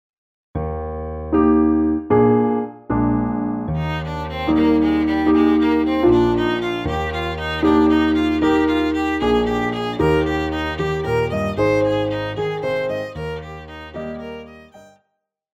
Classical
Solo with accompaniment
Metronome